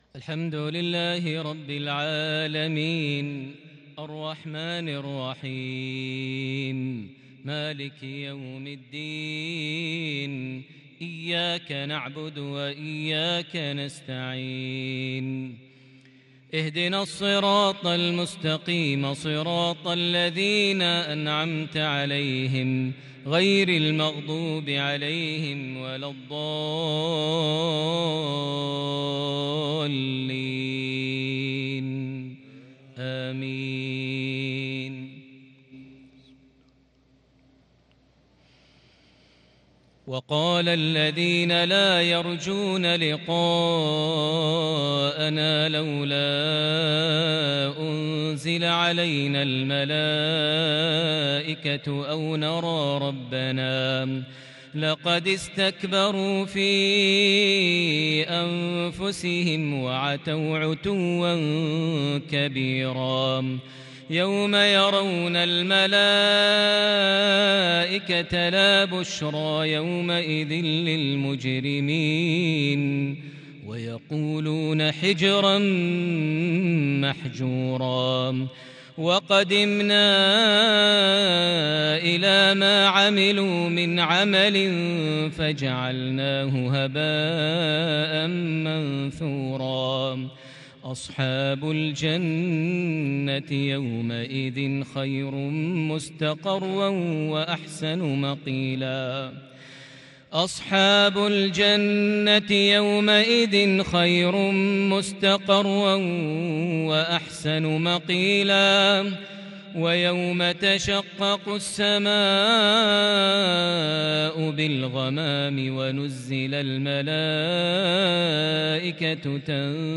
عشائية بديعة بترتيل أسطوري بالكرد من سورة الفرقان (21-40) | الأحد 18 شوال 1442هـ > 1442 هـ > الفروض - تلاوات ماهر المعيقلي